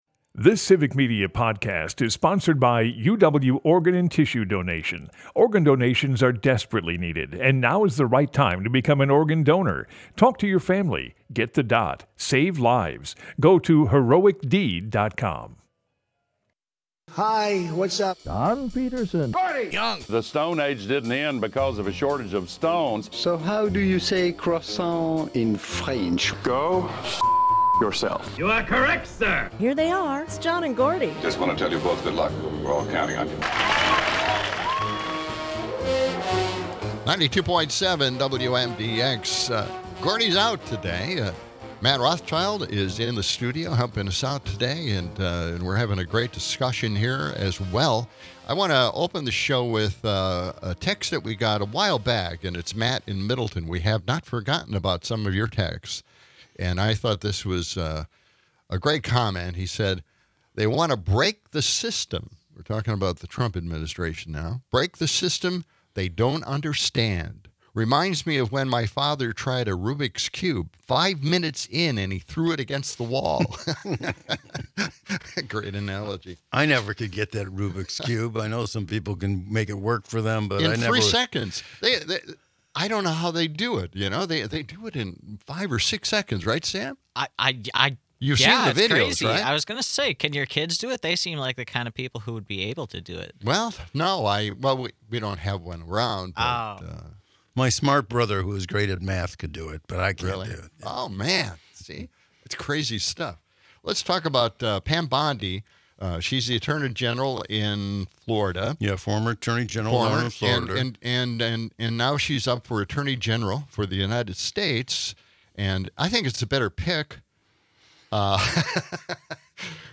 Broadcasts live 6 - 8am weekdays in Madison.